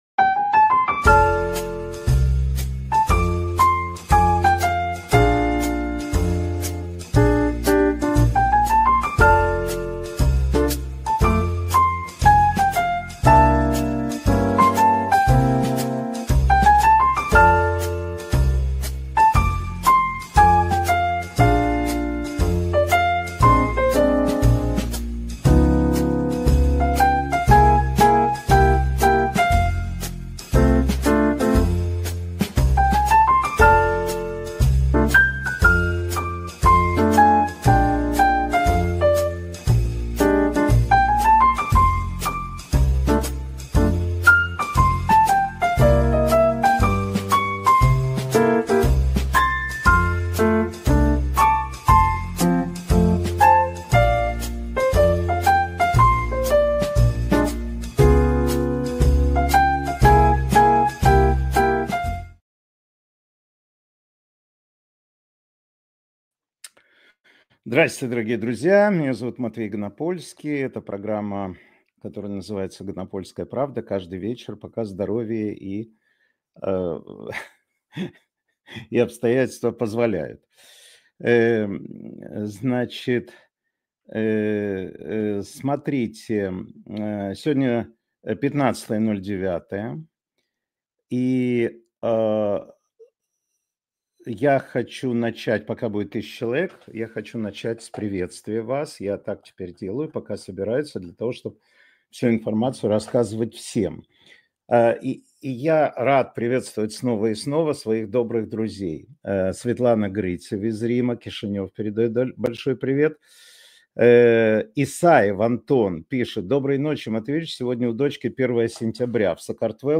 Информационно-аналитическая программа Матвея Ганапольского